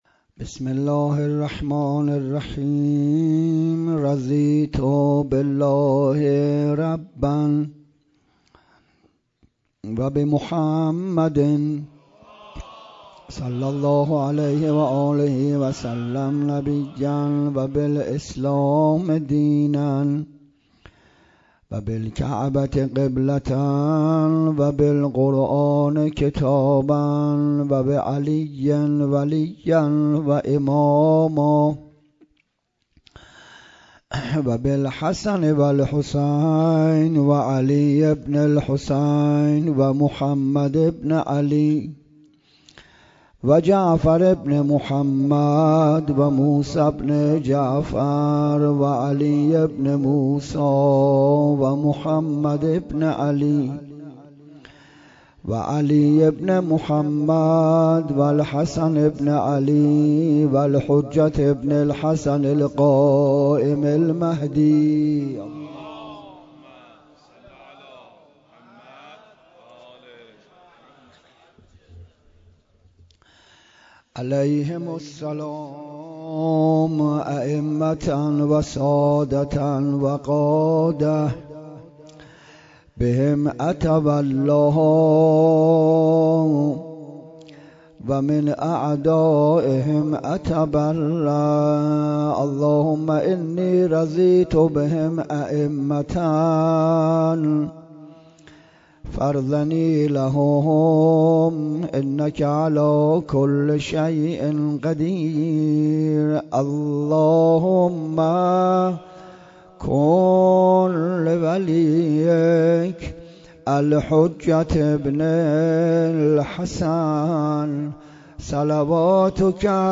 29 محرم 97 - مسجد الوندیه - علت غیبت و در کجا غیبت کردن اشکال ندارد
سخنرانی